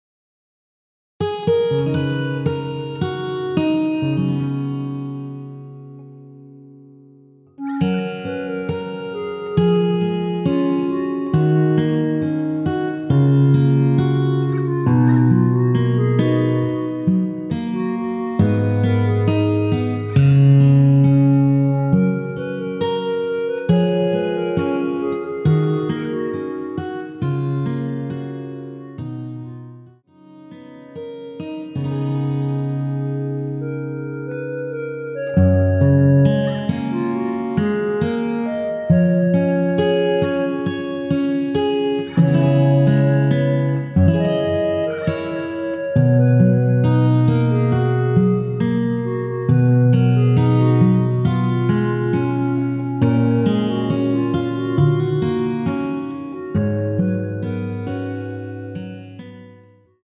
원키에서(+2)올린 멜로디 포함된 MR입니다.
F#
앞부분30초, 뒷부분30초씩 편집해서 올려 드리고 있습니다.
축가 MR